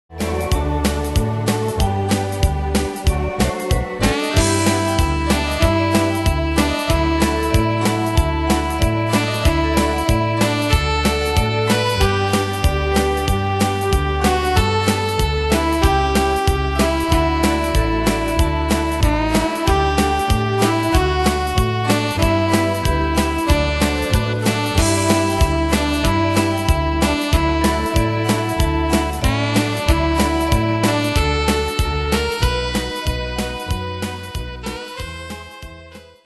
Style: Oldies Ane/Year: 1953 Tempo: 94 Durée/Time: 2.23
Danse/Dance: Swing Cat Id.